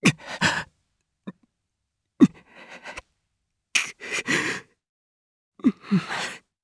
Siegfried-Vox_Sad_jp.wav